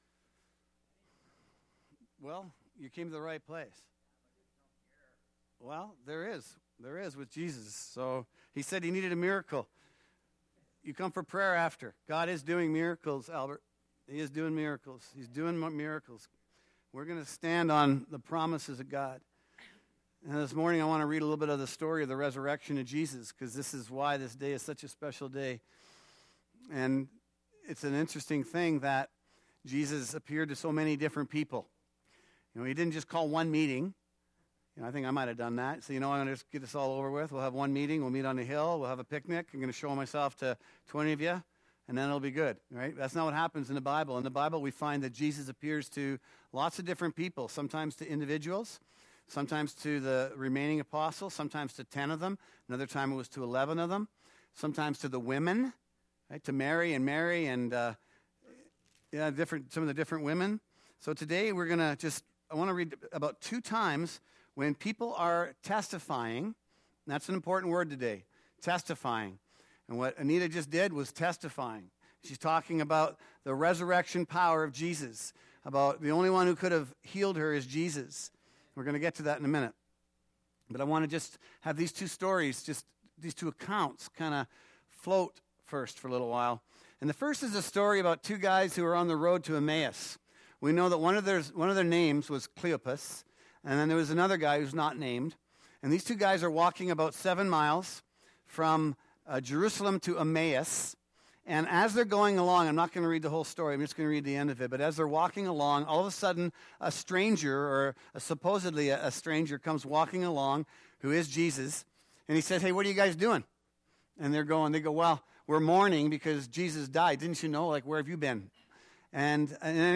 April 1 Easter Service | Potters Hands Ministries